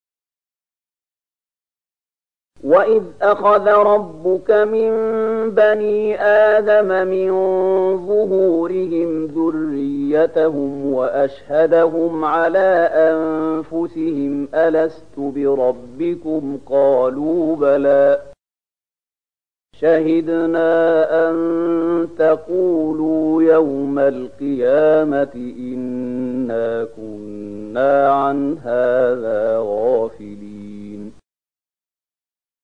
057008 Surat Al-Hadiid ayat 8 bacaan murattal ayat oleh Syaikh Mahmud Khalilil Hushariy: